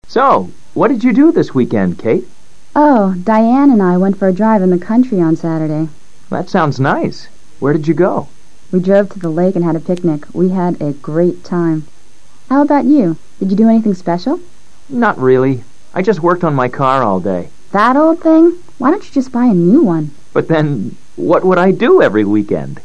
LISTENING TEST 9/16: Ahora, basándote en los comentarios del diálogo anterior, selecciona la opción más adecuada para cada pregunta.